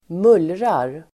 Uttal: [²m'ul:rar]